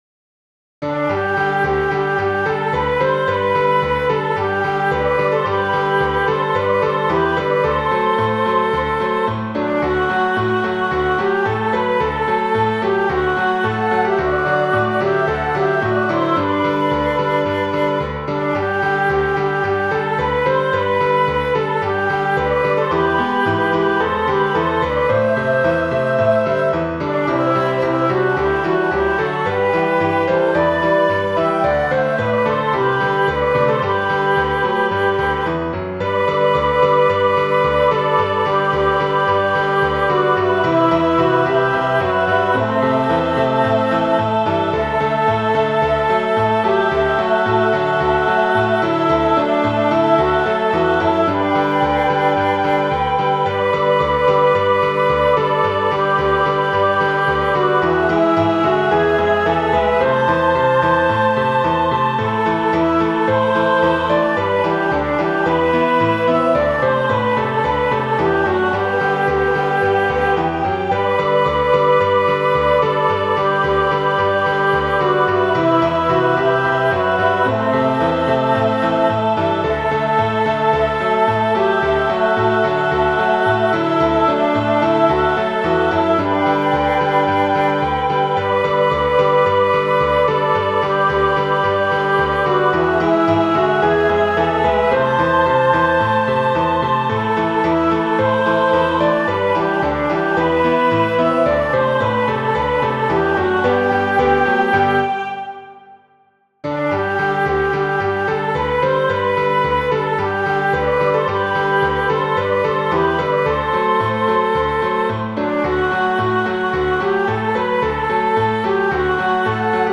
(versão piano/coro)